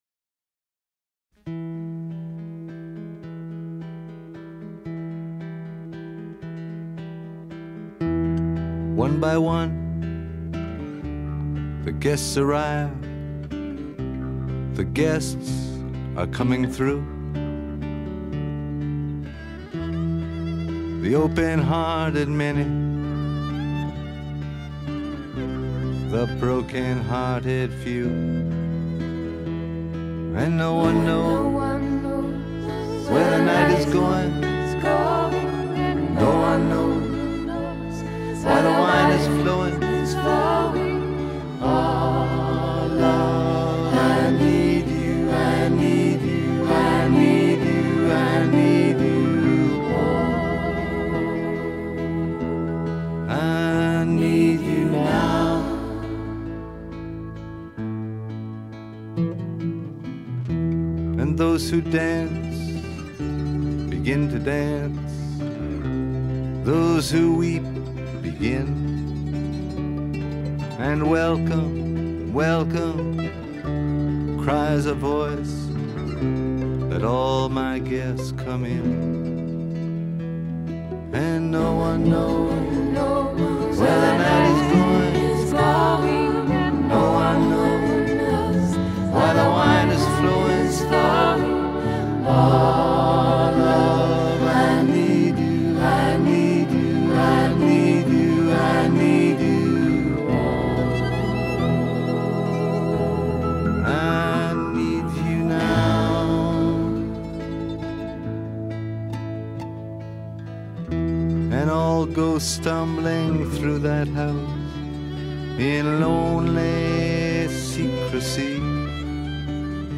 Folk Rock, Singer-Songwriter